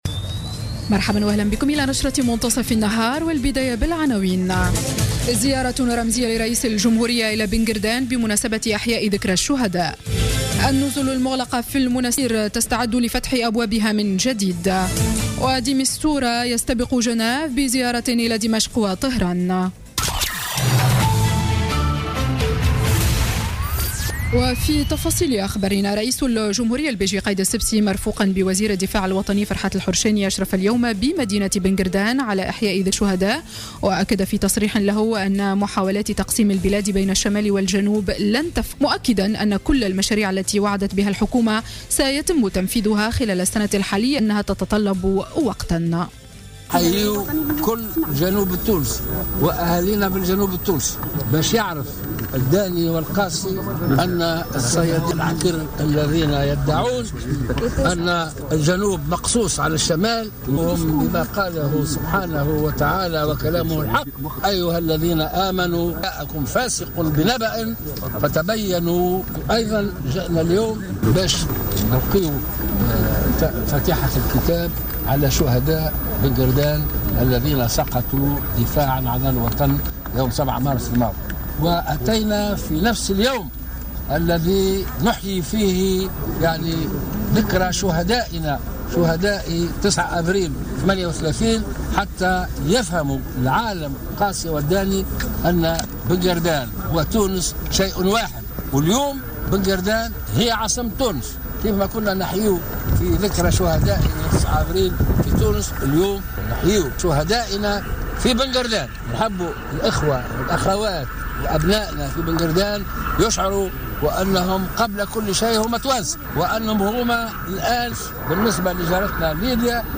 نشرة أخبار منتصف النهار ليوم السبت 09 أفريل 2016